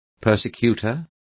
Complete with pronunciation of the translation of persecutor.